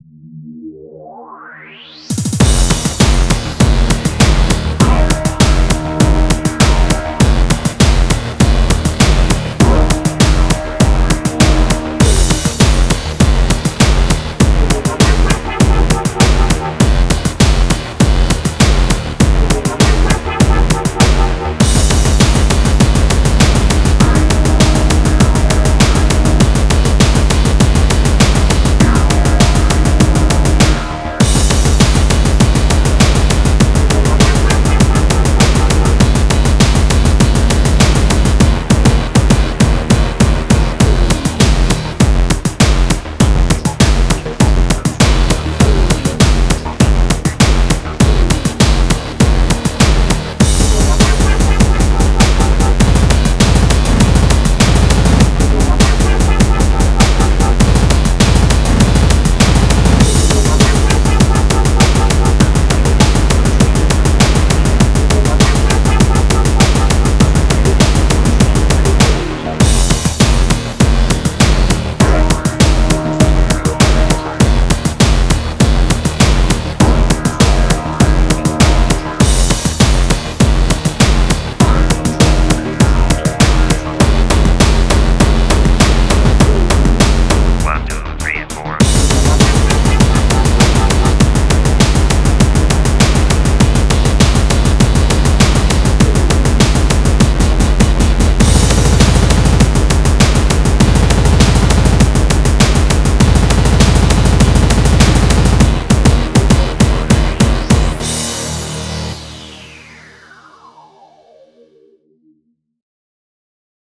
BPM100-800
Genre: Speedcore Techno